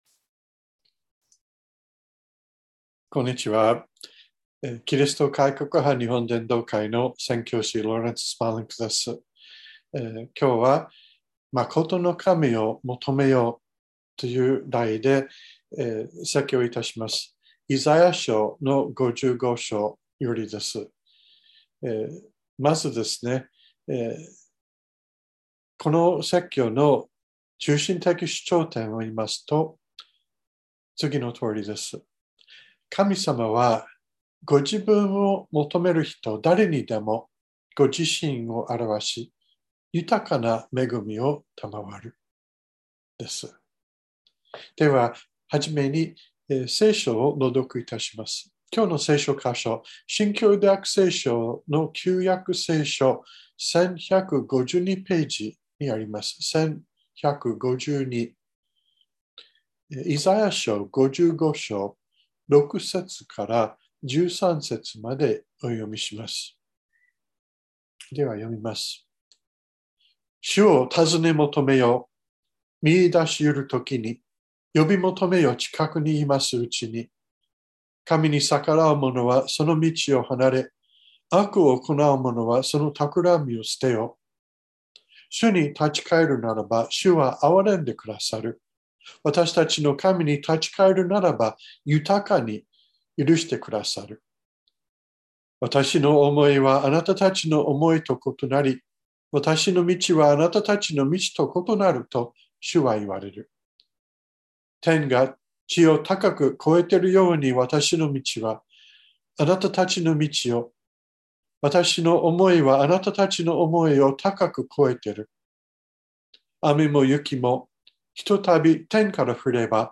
2022年02月27日朝の礼拝「まことの神を求めよう」川越教会
説教アーカイブ。